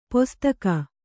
♪ postaka